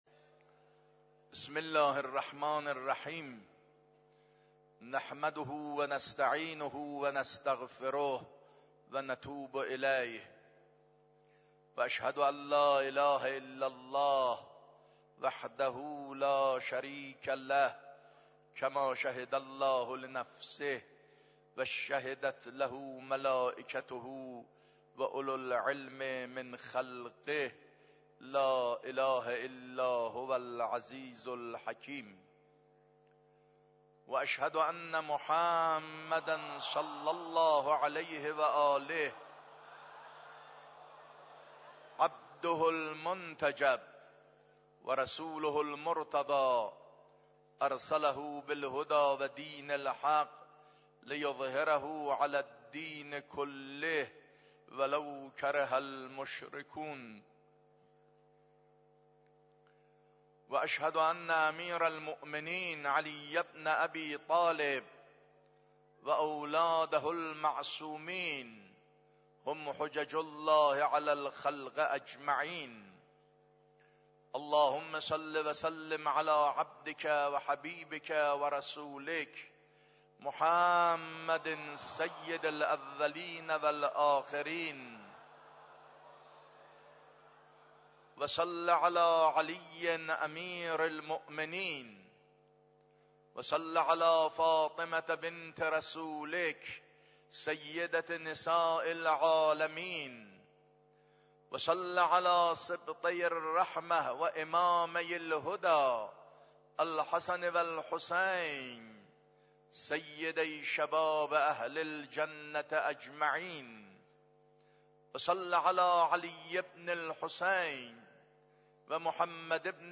خطبه دوم نماز جمعه 16 آبان ماه 93.mp3